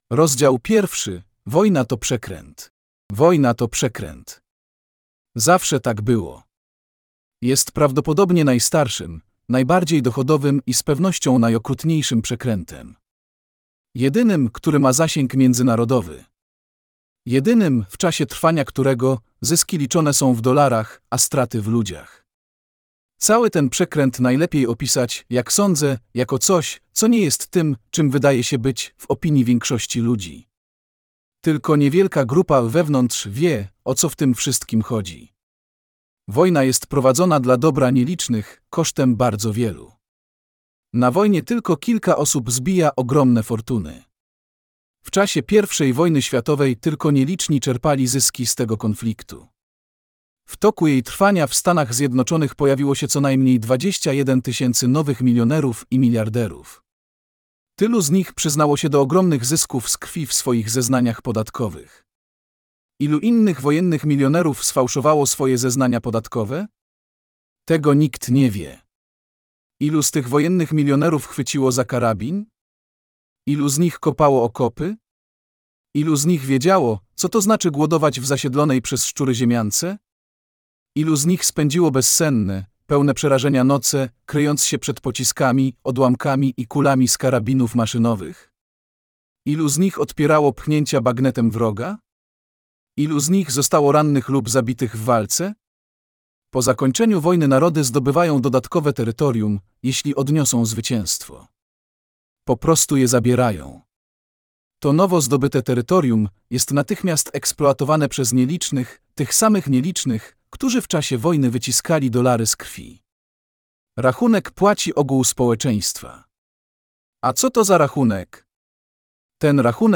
Wojna to przekręt! - Smedley D. Butler - audiobook